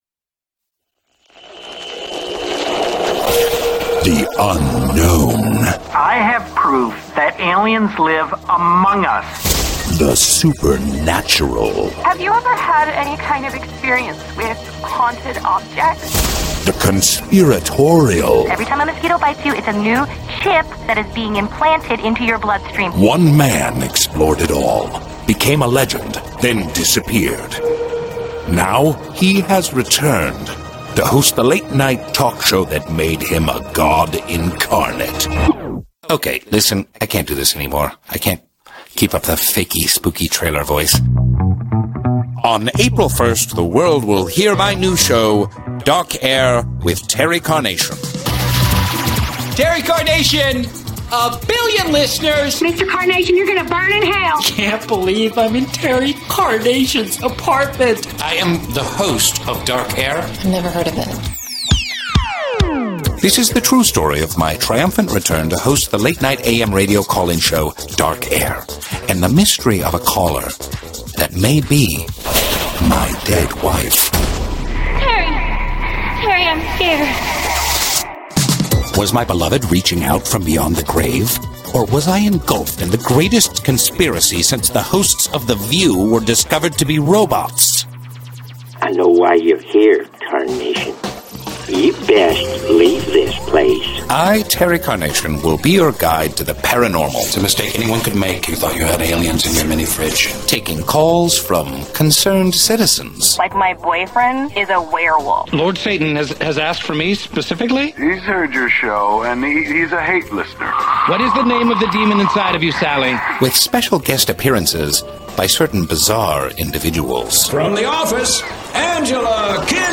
RAINN WILSON - "TERRY CARNATION"
A promo for "Dark Air with Terry Carnation":